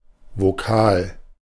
Ääntäminen
Synonyymit Selbstlaut Ääntäminen Tuntematon aksentti: IPA: [voˈkaːl] Haettu sana löytyi näillä lähdekielillä: saksa Käännös Konteksti Ääninäyte Substantiivit 1. vowel kielitiede, fonetiikka US Artikkeli: der .